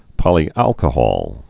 (pŏlē-ălkə-hôl, -hŏl)